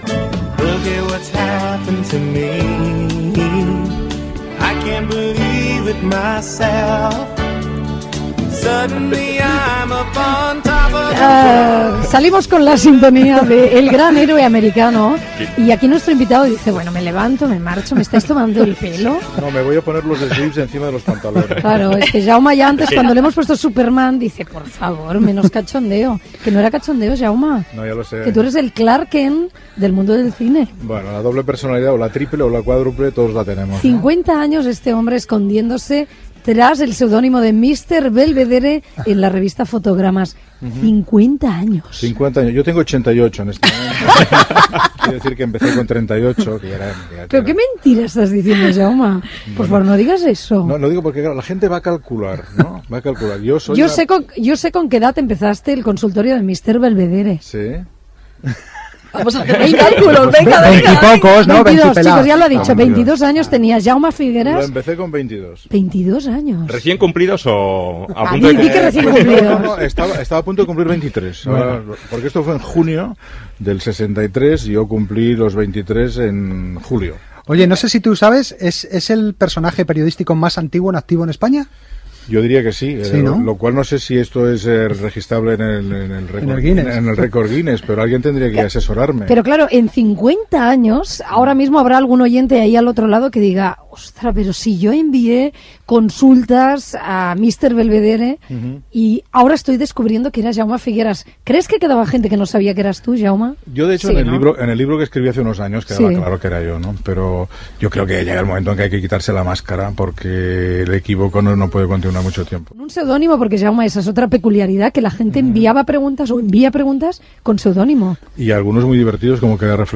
Entrevista a Jaume Figueras, Mr. Belvedere, de la revista "Fotogramas".
Info-entreteniment